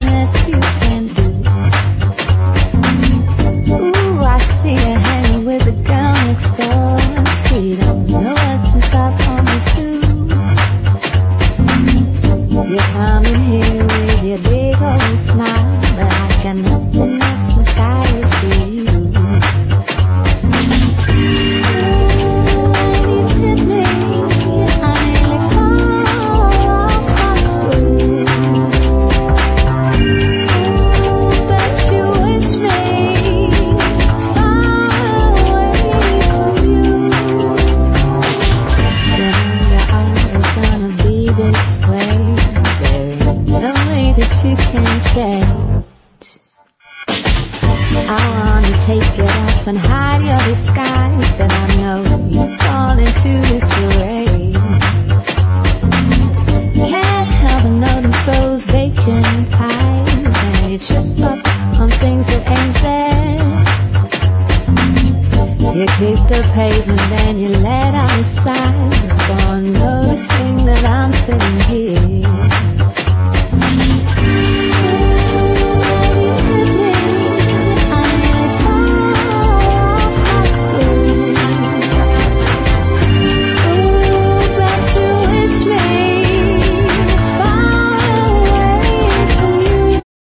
sublimely soulful vocalist